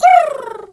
pigeon_2_stress_04.wav